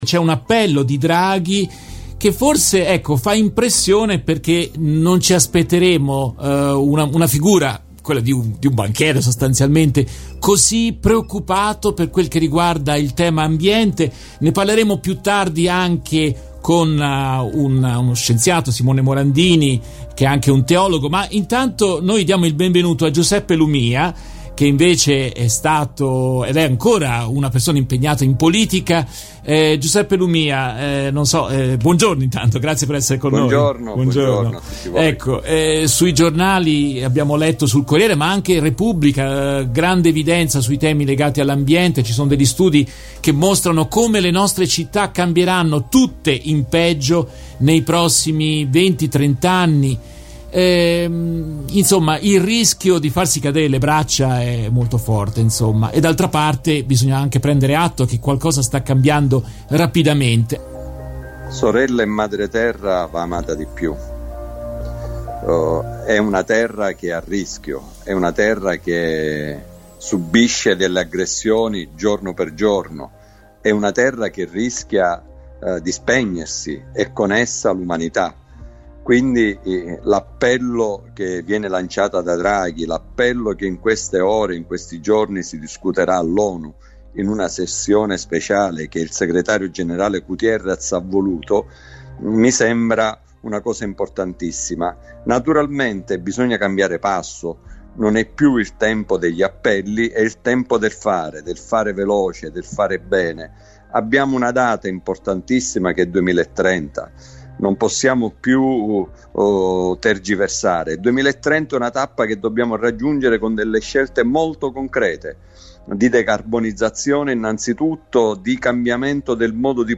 In questa intervista tratta dalla diretta RVS del 21 settembre 2021